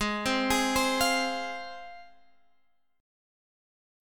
Listen to G#6 strummed